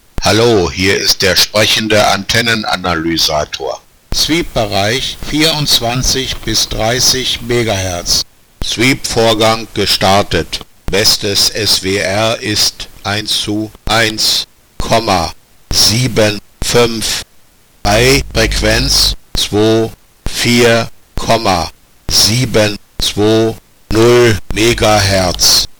Anschaltung einer Sprachausgabeeinheit
Nach Sweep-Abschluss erfolgt dann eine weitere Sprachausgabe, die sich etwa folgendermassen anhören kann:
ant_analyzer_soundsample.mp3